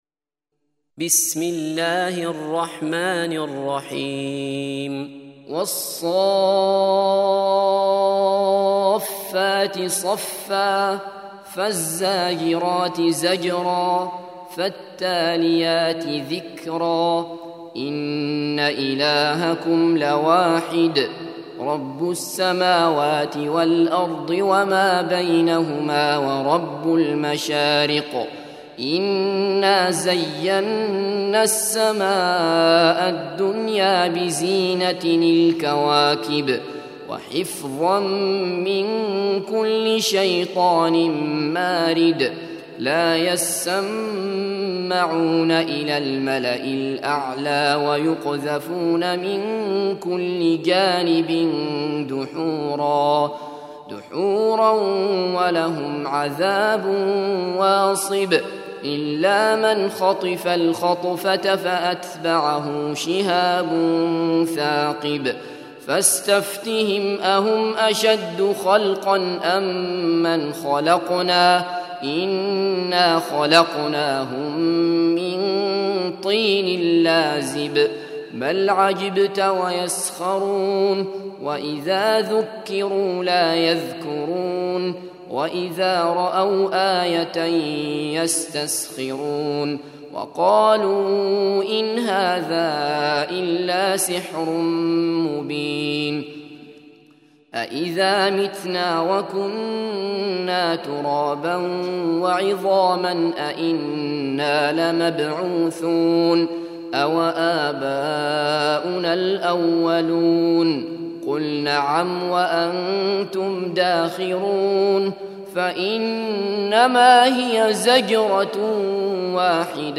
37. Surah As-S�ff�t سورة الصافات Audio Quran Tarteel Recitation
Surah Sequence تتابع السورة Download Surah حمّل السورة Reciting Murattalah Audio for 37.